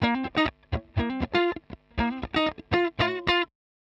120_Guitar_funky_riff_C_6.wav